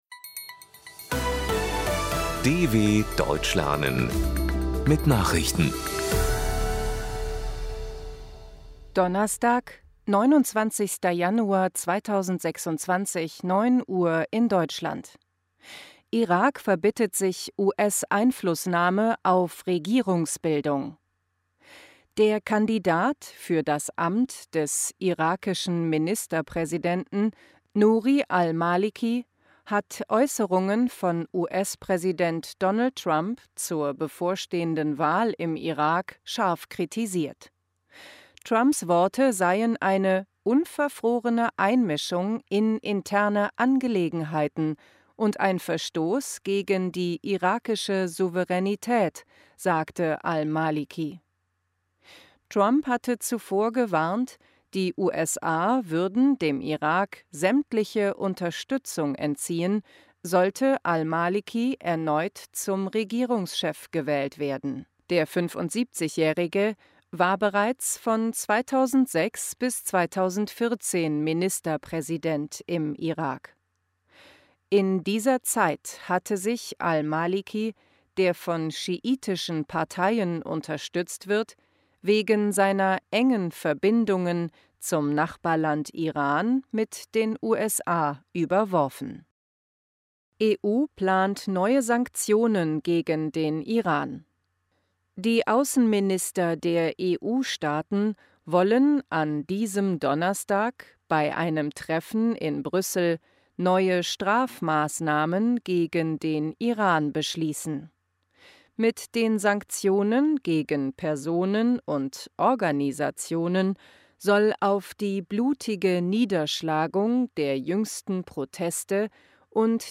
29.01.2026 – Langsam Gesprochene Nachrichten
Trainiere dein Hörverstehen mit den Nachrichten der DW von Donnerstag – als Text und als verständlich gesprochene Audio-Datei.